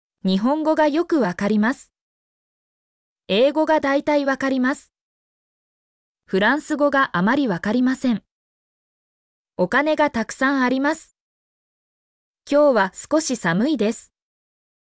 mp3-output-ttsfreedotcom-24_2lRCMsmR.mp3